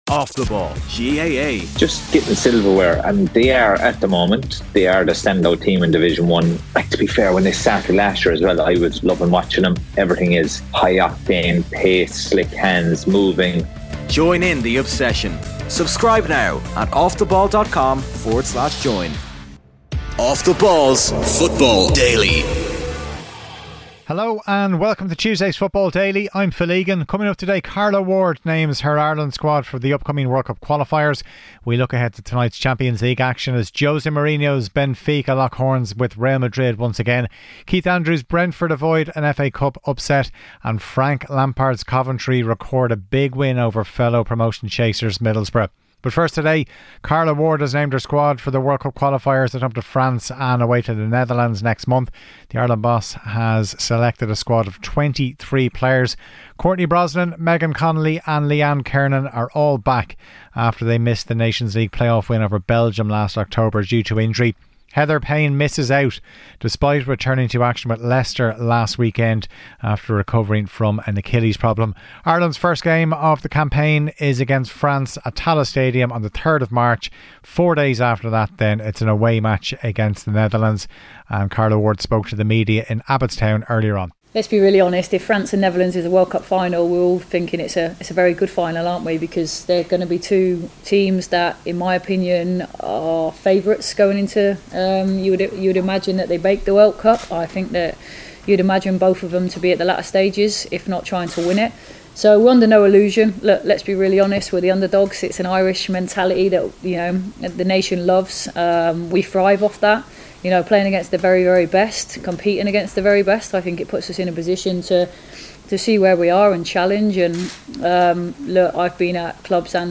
Also hear from Thomas Tuchel after he names his England squad.